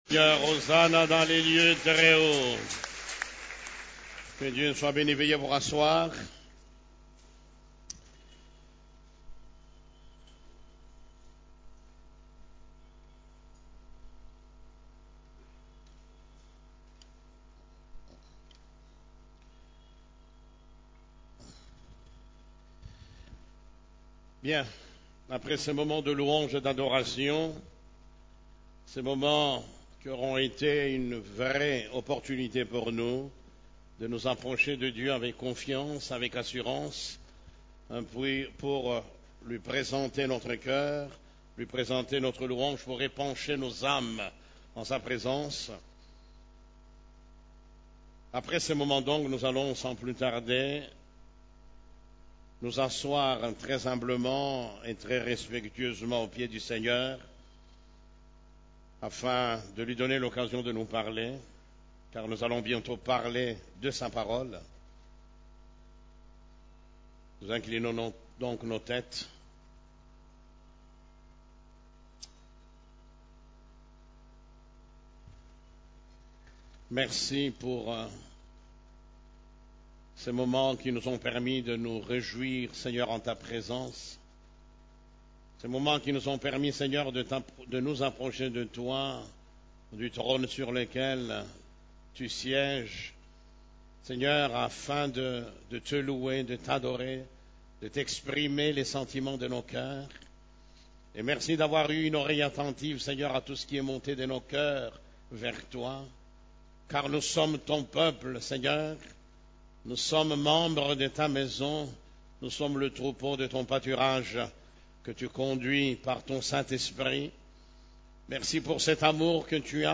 CEF la Borne, Culte du Dimanche, Comment tenir son engangement jusqu'au bout